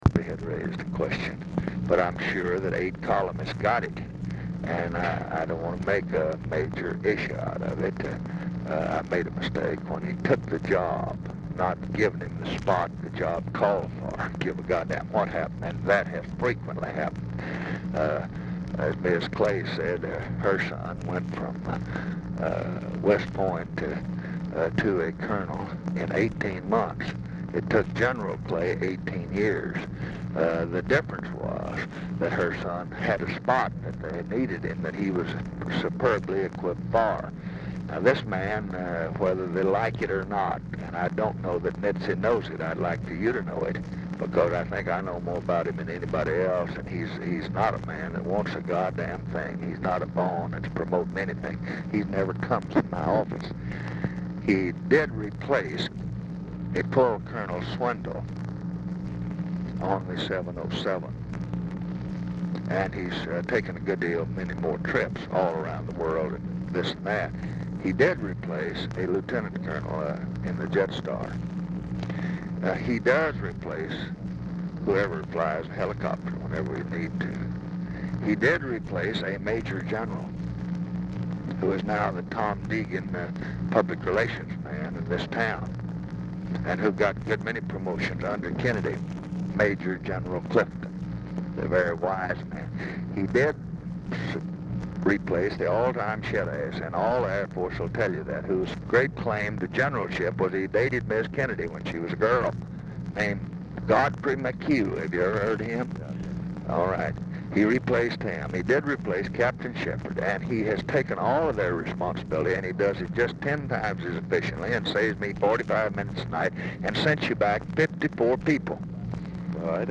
CLIFFORD IS DIFFICULT TO HEAR
Format Dictation belt
Location Of Speaker 1 Mansion, White House, Washington, DC
Specific Item Type Telephone conversation